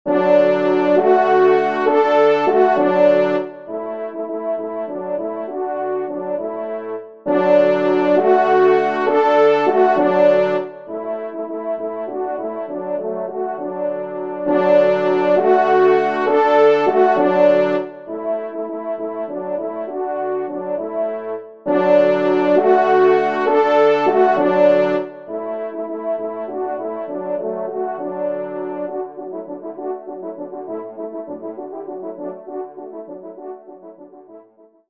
ENSEMBLE
Millescamps-AFT-10-Indiana_ENS_EXT.mp3